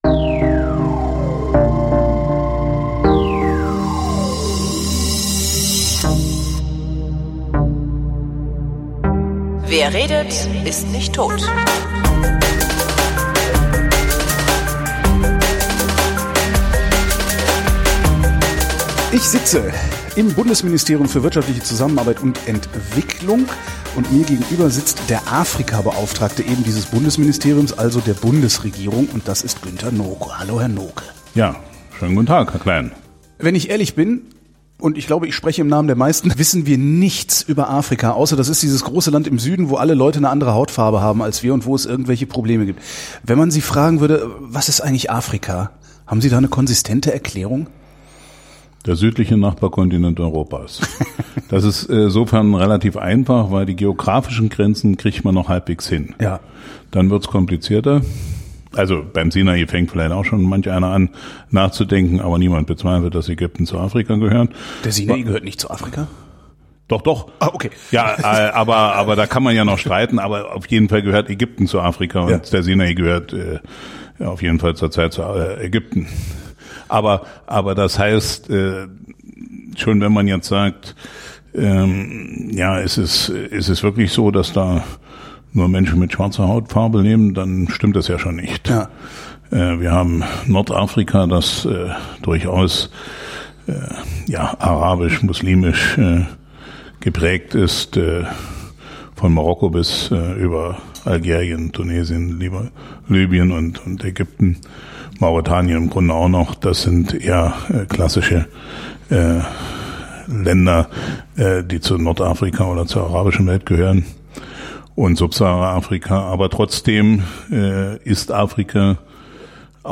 wrint: gespräche zum runterladen
Und dann rede ich mit Günter Nooke über eben diesen Kontinent und einen Teil der Komplikationen, insbesondere über Afrikas wirtschaftliche Entwicklung.